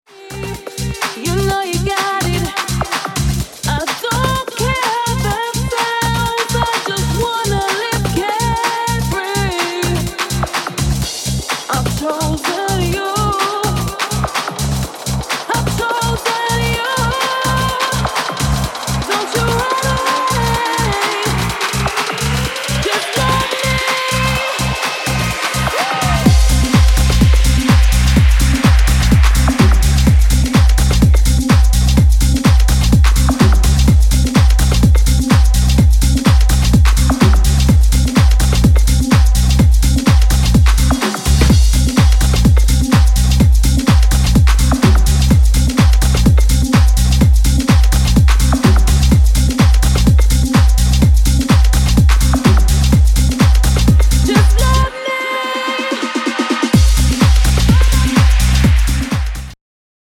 House, Tech House y Techno más bailable